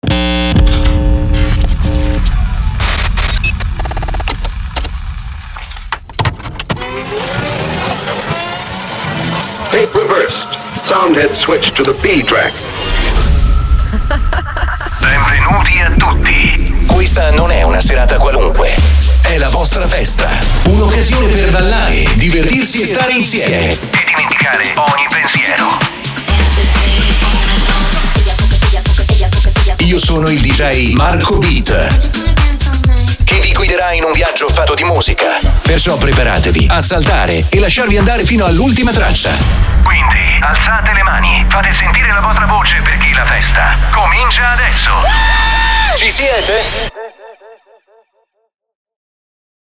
Never any Artificial Voices used, unlike other sites.
Male
Yng Adult (18-29), Adult (30-50)
My voice is fresh and warm depends by the style of your text/work.
Words that describe my voice are warm, fresh, clear.